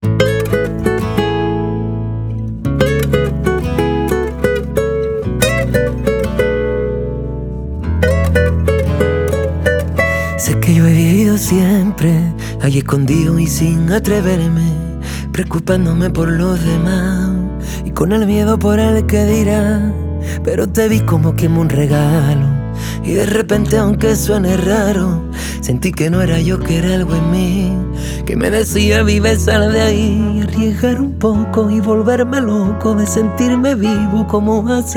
Pop Latin Pop Latino
Жанр: Поп музыка